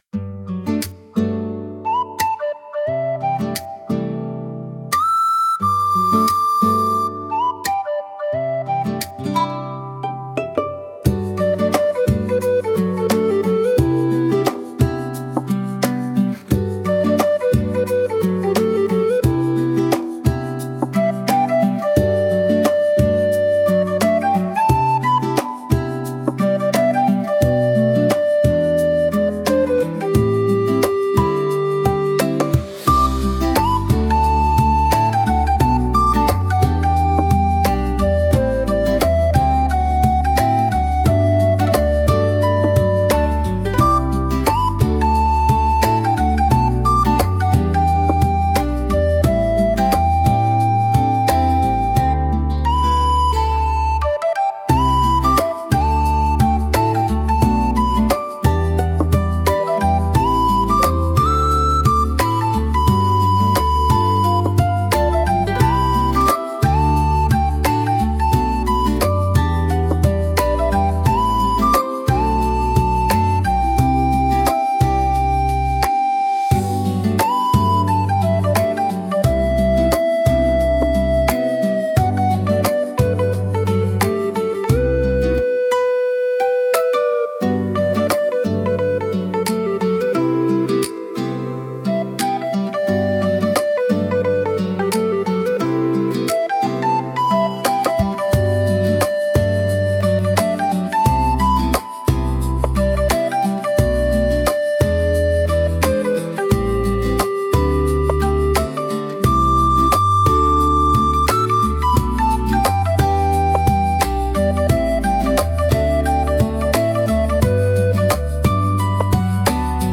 聴く人に安心感と温かみを届け、リラックスできる空間づくりにぴったりのジャンルです。